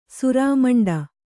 ♪ surāmaṇḍa